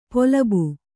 ♪ polabu